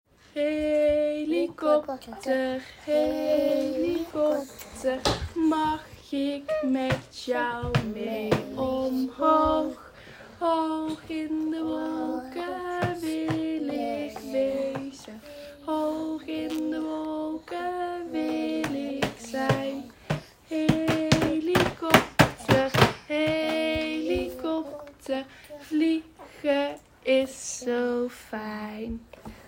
Helikopter.mp3